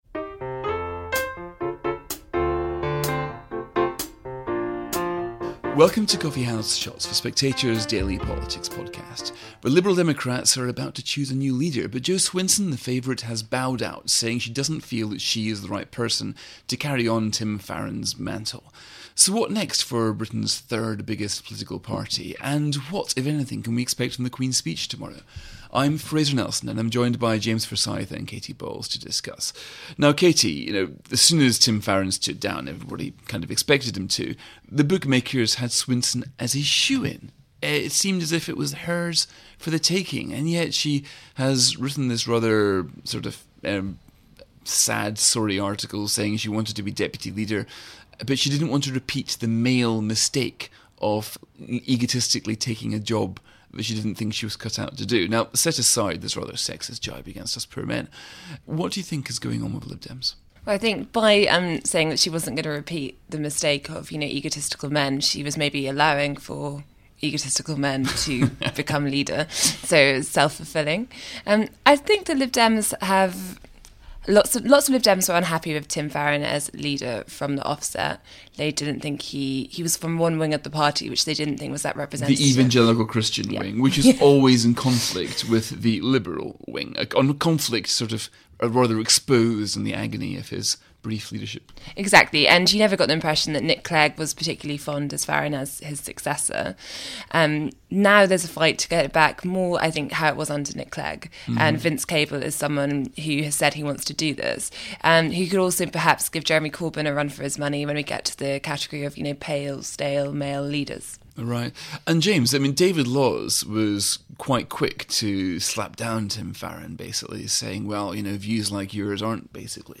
Presented by Fraser Nelson.